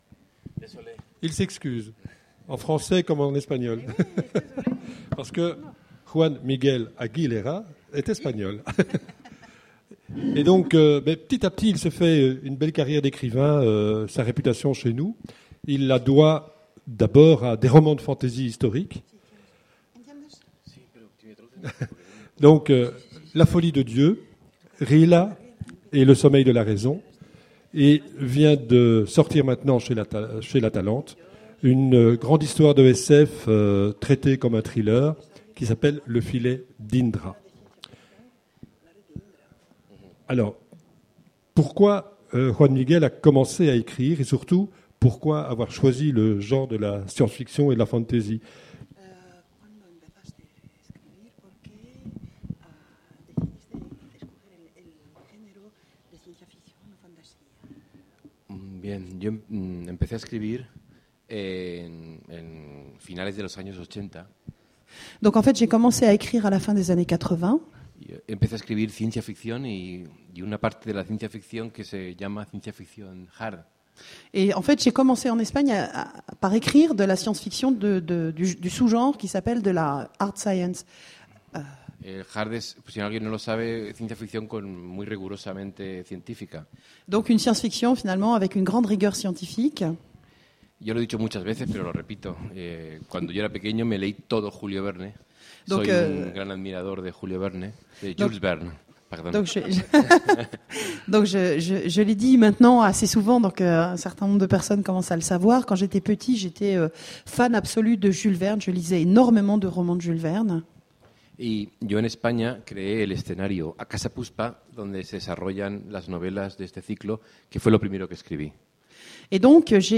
Conférence Imaginales 2010
Rencontre avec un auteur